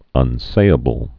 (ŭn-sāə-bəl)